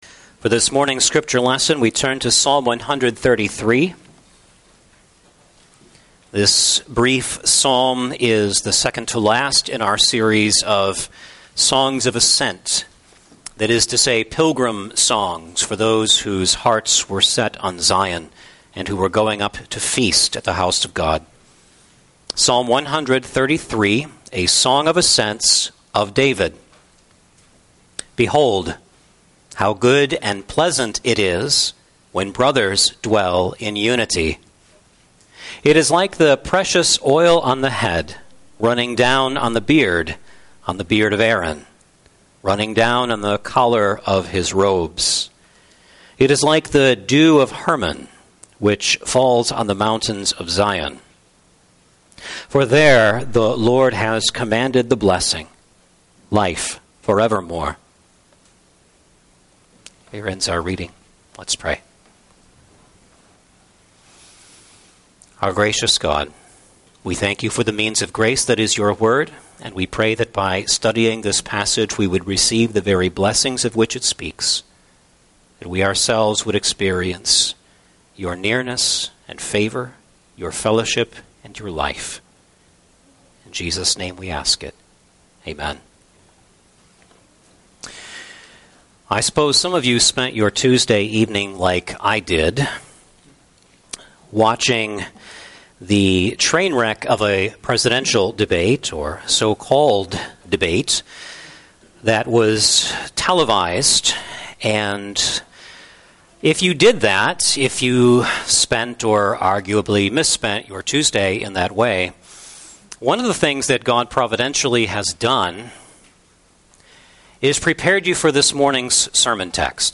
Psalms of Ascents Passage: Psalm 133 Service Type: Sunday Evening Service « Skilled Master Builders Sabbath